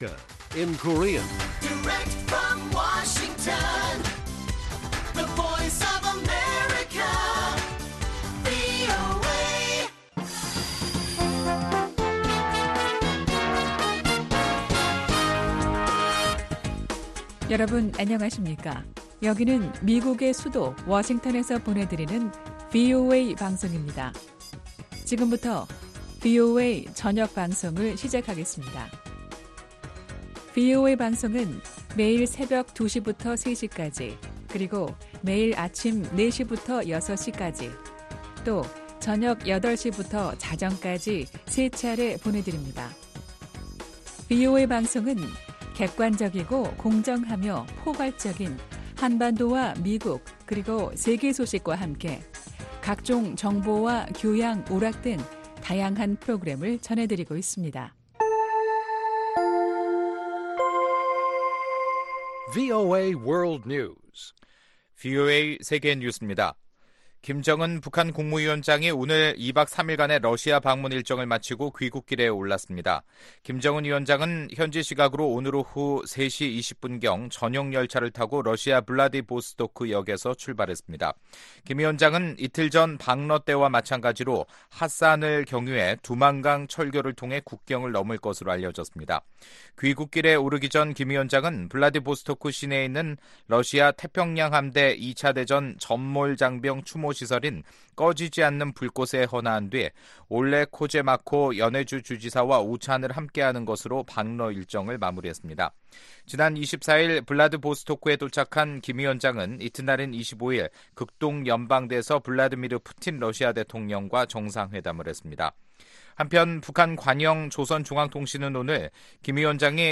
VOA 한국어 간판 뉴스 프로그램 '뉴스 투데이', 2019년 4월 26일 1부 방송입니다. 마이크 폼페오 미 국무장관은 교착 상태에 있는 북한과의 핵 협상이 평탄치 않다면서도 완전한 비핵화를 달성할 수 있을 것이라고 말했습니다. 북한과 러시아의 정상회담은 대미 협상을 위한 새로운 지렛대가 되지 못했다고 미국의 전문가들이 밝혔습니다.